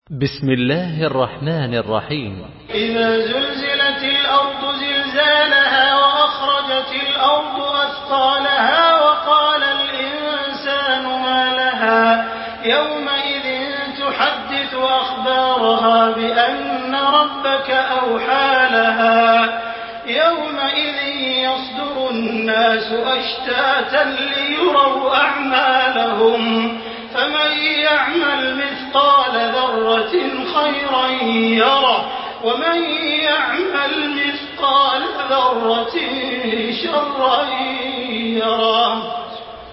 Makkah Taraweeh 1428
Murattal Hafs An Asim